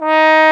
Index of /90_sSampleCDs/Roland L-CDX-03 Disk 2/BRS_Trombone/BRS_Tenor Bone 4